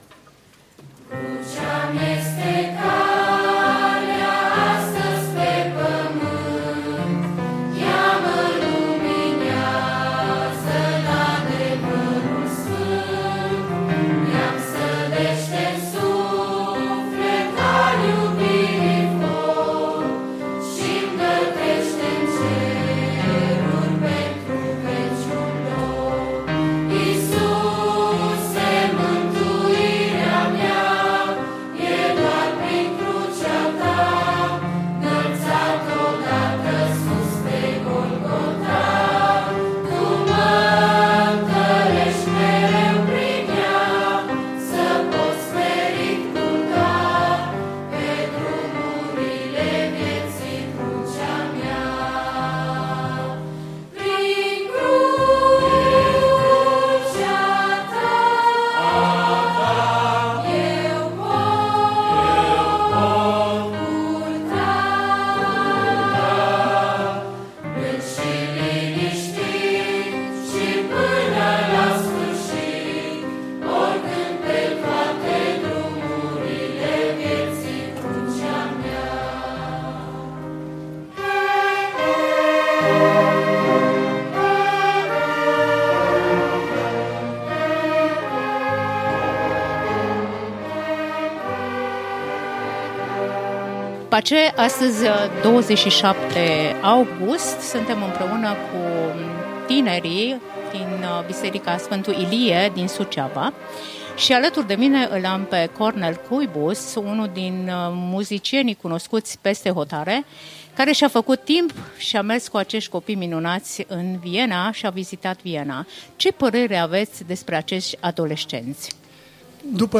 Reportaj despre turneul orchestrei bisericii Sfântu Ilie în Austria